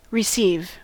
Ääntäminen
IPA : /ɹɪˈsiːv/